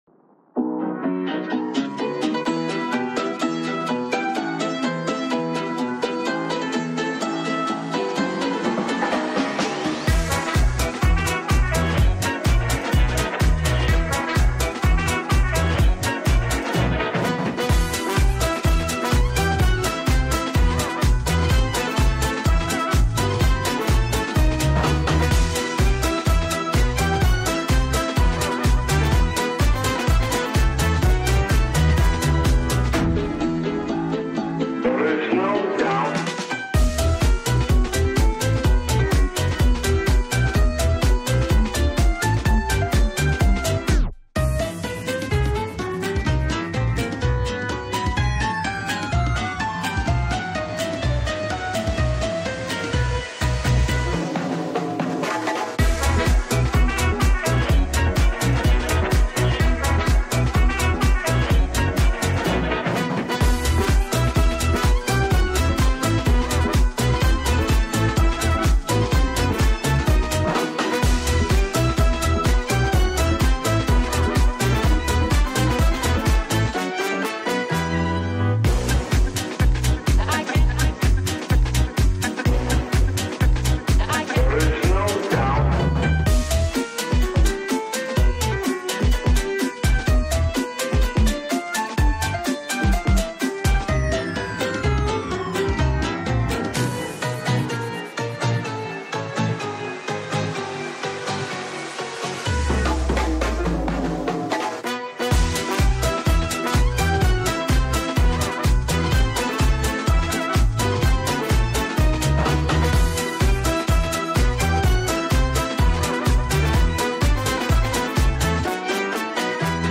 Electro Swing Mix